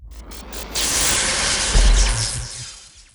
x1_battle_aidisheng_skill02.wav